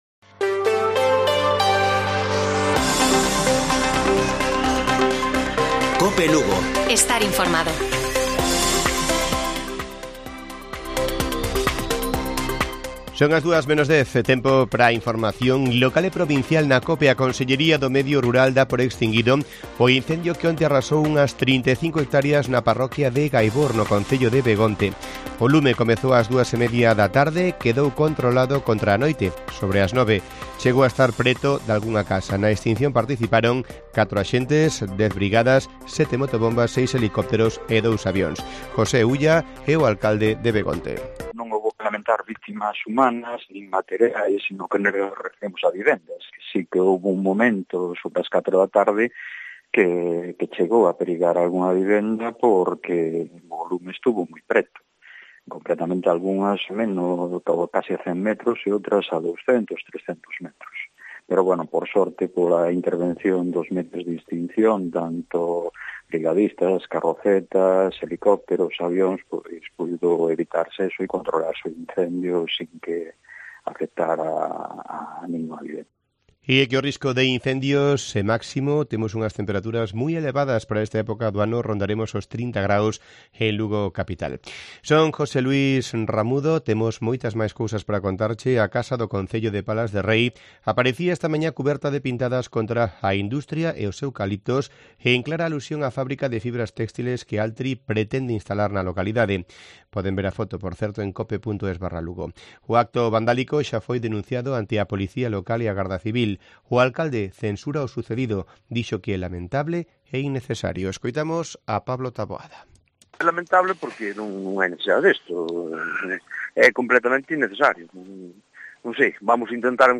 Informativo Mediodía de Cope Lugo. 10 de mayo. 13:50 horas